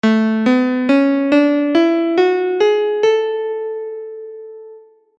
The Ionian mode of right-hand Nicetone, with 3:2:1 step size ratios
RH_Nice_Ionian.mp3